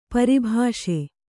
♪ pari bhāṣe